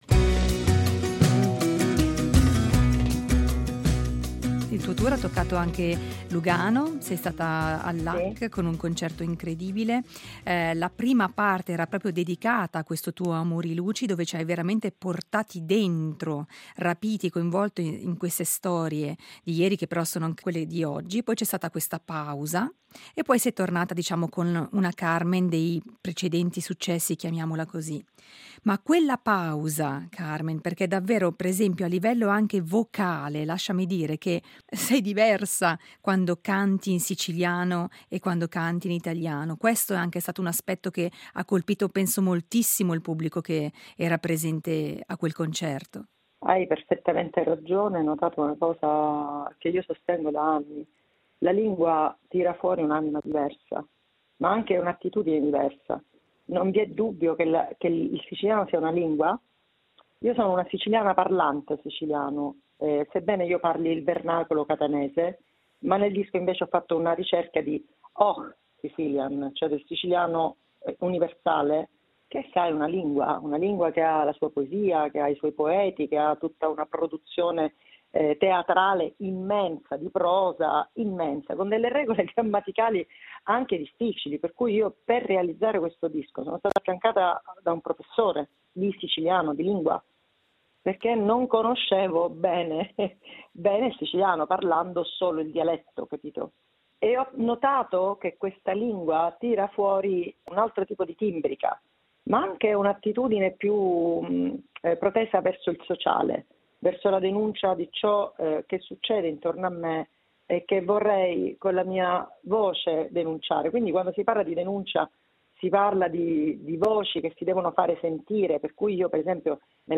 Intervista a Carmen Consoli (2./2)